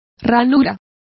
Complete with pronunciation of the translation of slot.